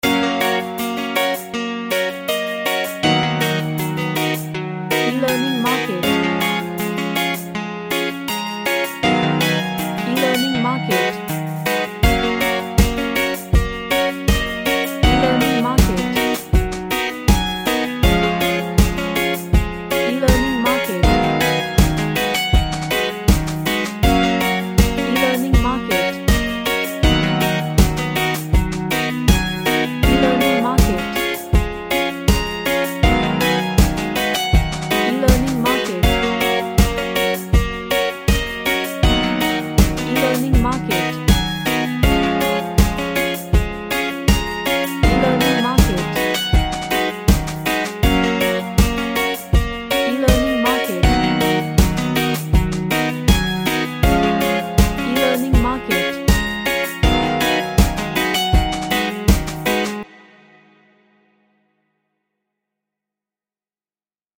A Happy reggae track with lots of percs.
Happy